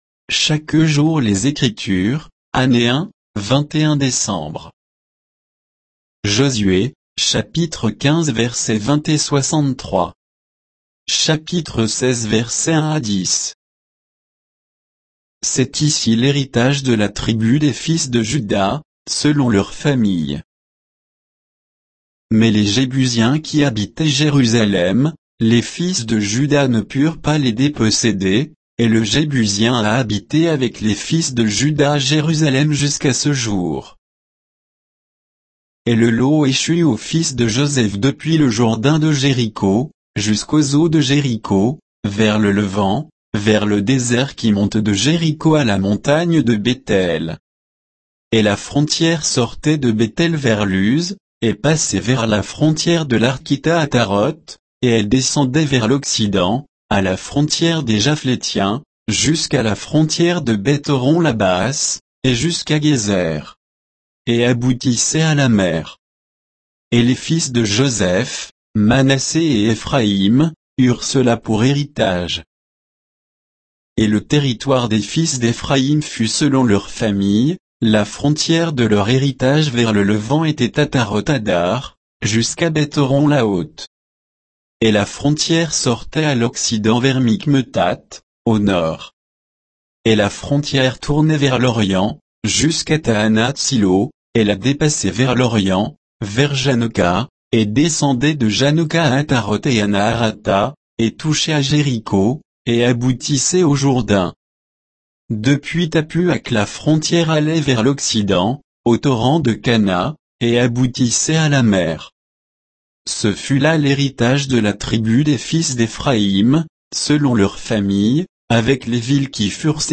Méditation quoditienne de Chaque jour les Écritures sur Josué 15, 20 et 63; 16, 1-10